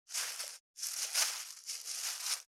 617ゴミ袋,スーパーの袋,袋,買い出しの音,ゴミ出しの音,袋を運ぶ音,
効果音